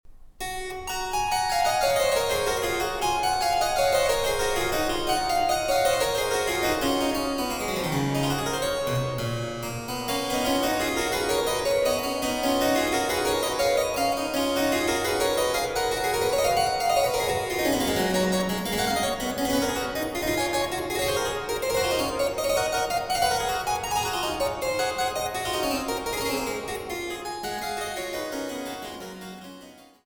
Cembalo